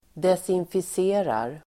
Ladda ner uttalet
Uttal: [desinfis'e:rar]
desinficerar.mp3